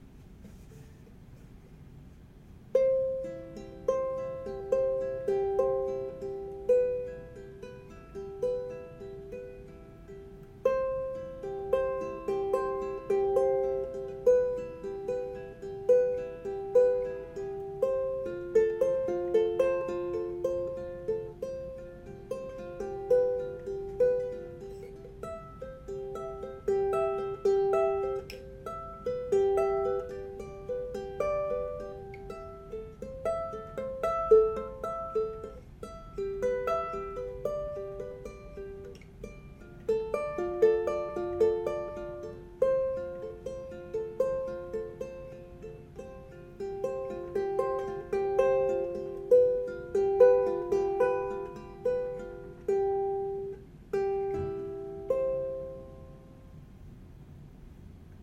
Ukulele aNueNue UC-10
Strings: Custom Set, Aquila Red + Living Water High G